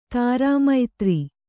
♪ tārā maitri